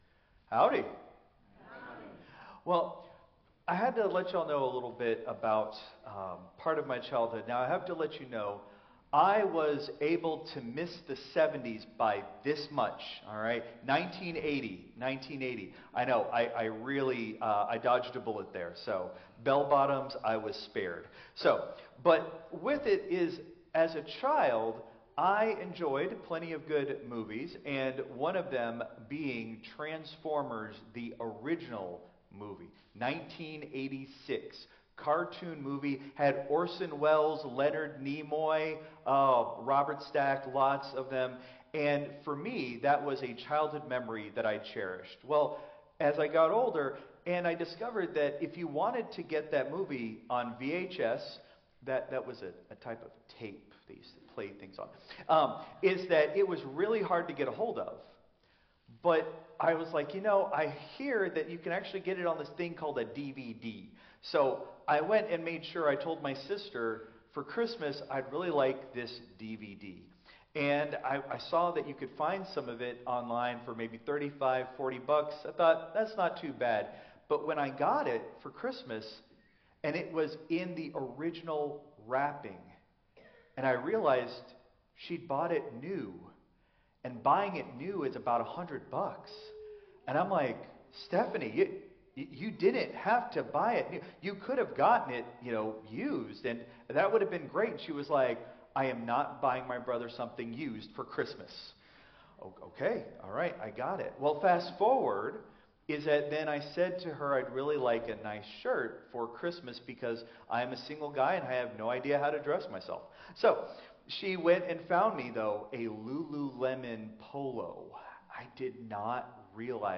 Christ Memorial Lutheran Church - Houston TX - CMLC 2025-02-02 Sermon (Contemporary)